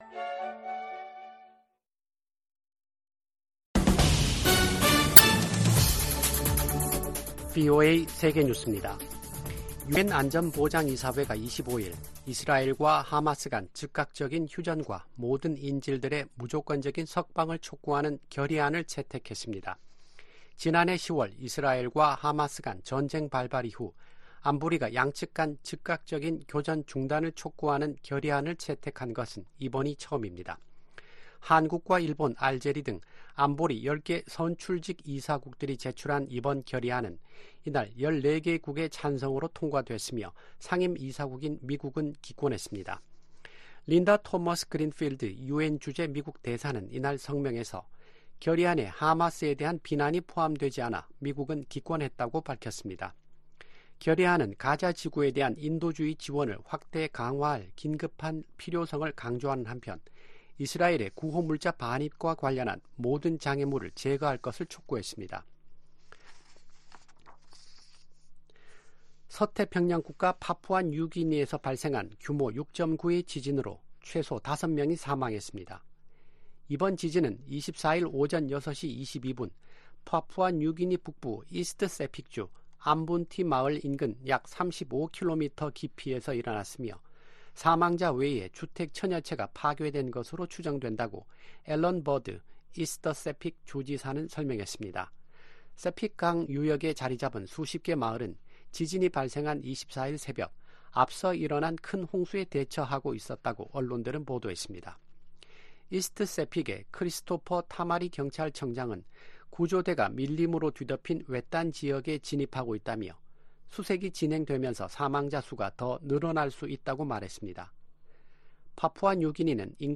VOA 한국어 아침 뉴스 프로그램 '워싱턴 뉴스 광장'입니다. 러시아가 우크라이나를 향해 최소 10차례에 걸쳐 북한제 탄도미사일 40여 발을 발사했다고 로버트 우드 유엔주재 미국 차석대사가 밝혔습니다. 김여정 북한 노동당 부부장은 기시다 후미오 일본 총리로부터 정상회담 제의를 받았다고 밝혔습니다.